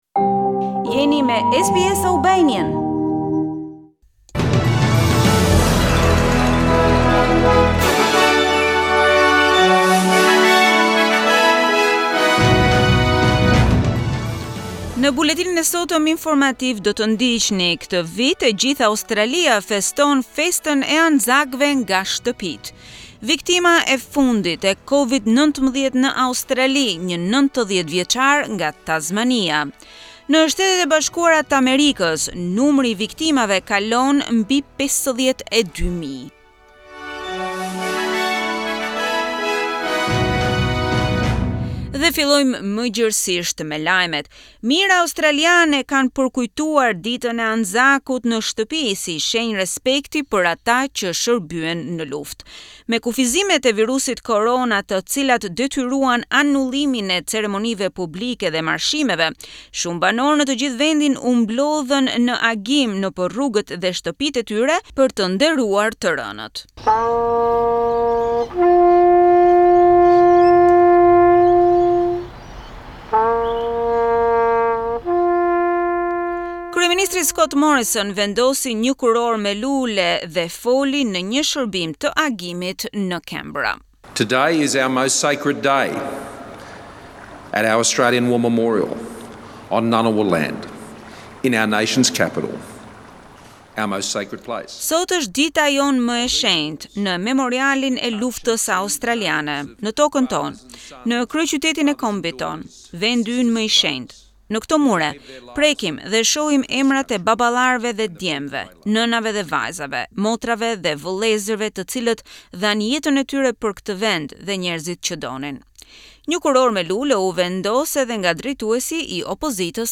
SBS News Bulletin - 25 April 2020